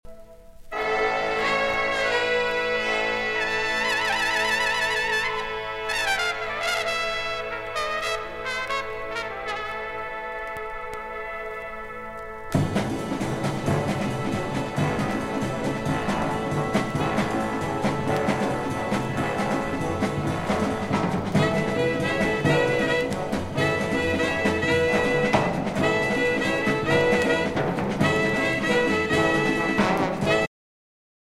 bass trombone
alto saxophone
tenor saxophone
baritone saxophone
Jazz vocals